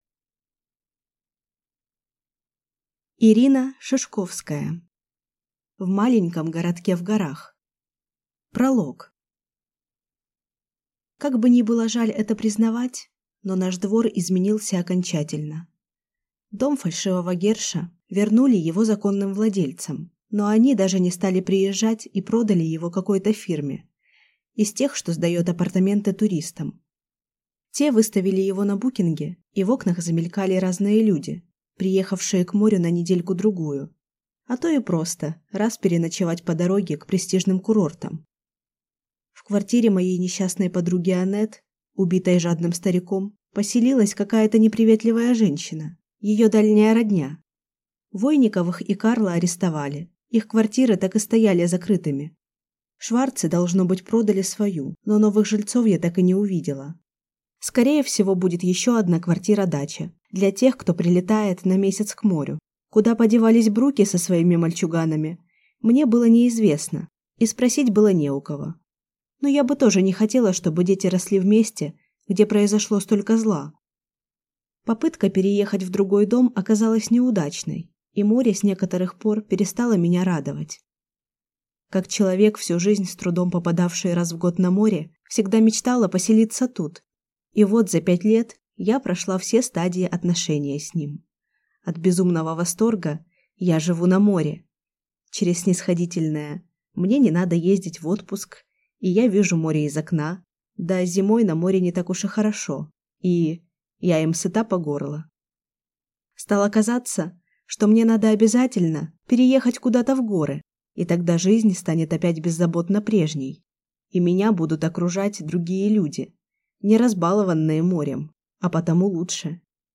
Аудиокнига В маленьком городке в горах | Библиотека аудиокниг